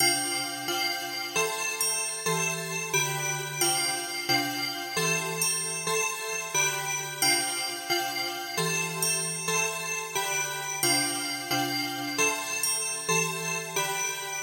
Water Hitting Hot Metal
标签： cooling heat heating hit hitting hot liquid metal pan sizzle sizzling steam stove water
声道立体声